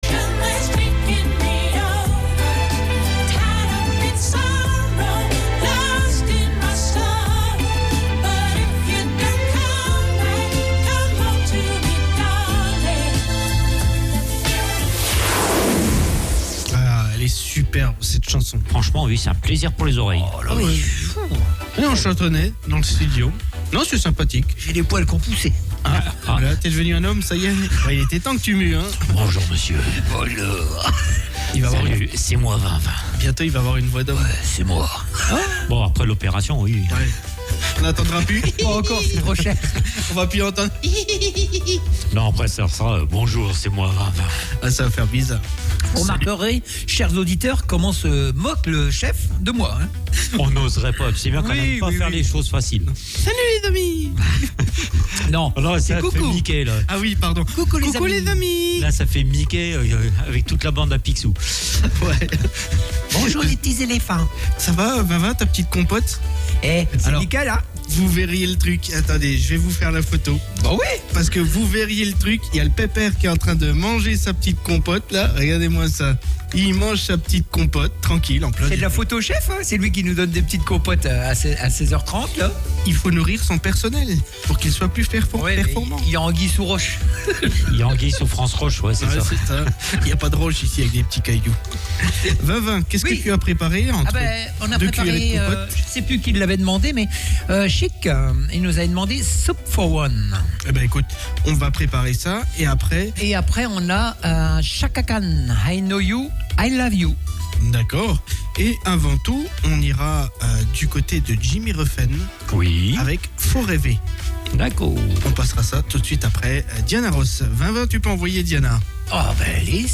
Tous les dimanches de 14h30 à 17h00En direct sur ANTENNE 87À (re)découvrir en podcast sur notre site web